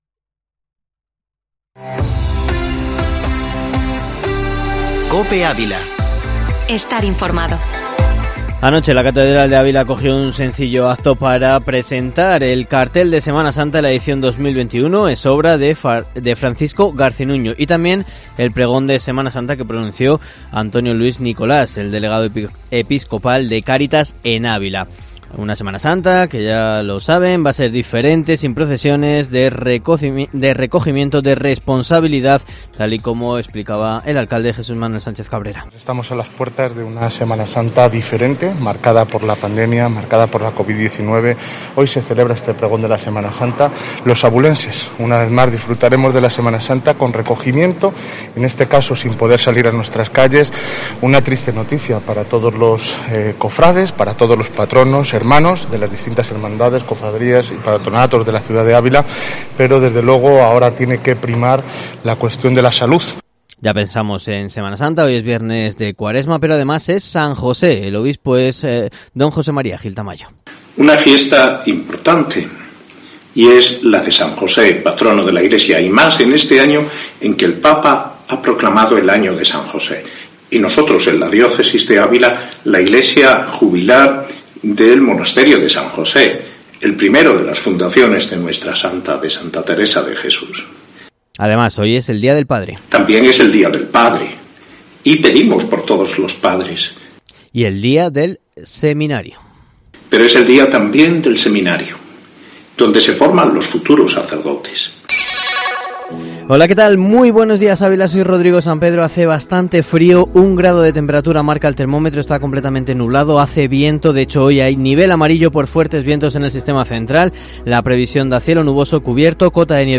Informativo matinal Herrera en COPE Ávila 19/03/2021